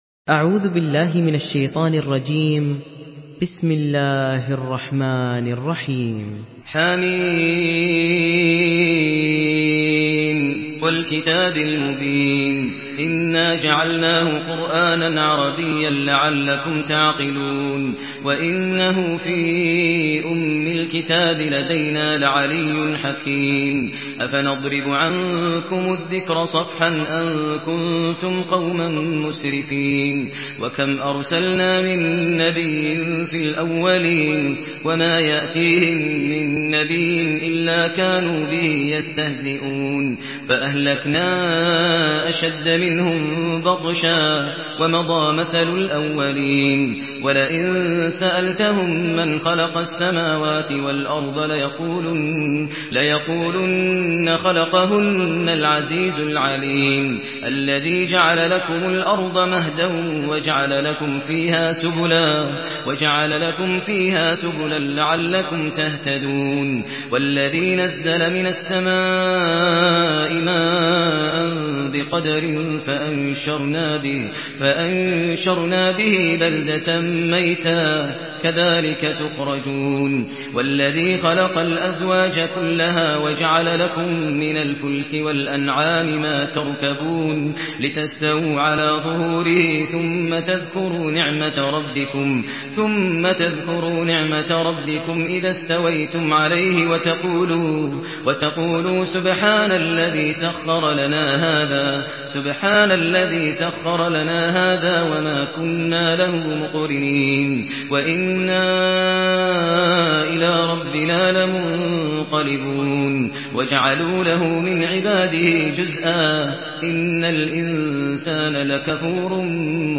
Surah Az-Zukhruf MP3 in the Voice of Maher Al Muaiqly in Hafs Narration
Murattal